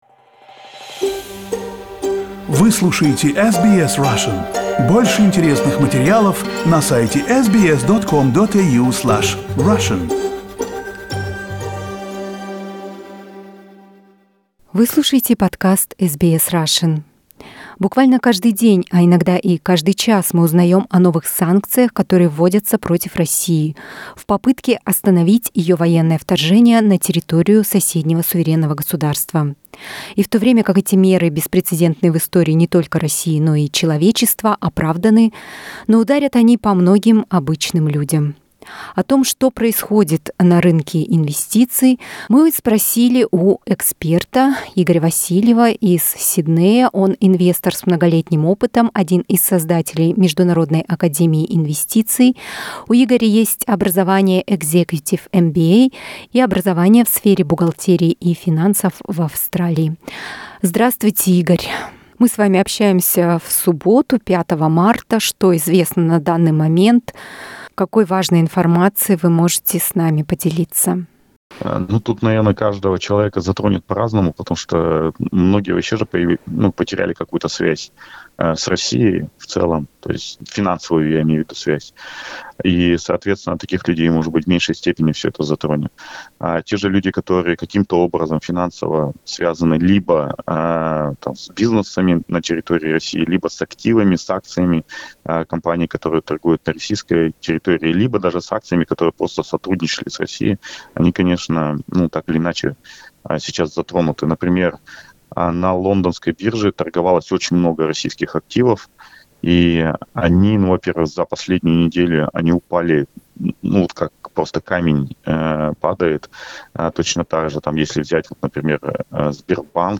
What is happening with the ruble? Interview with an investor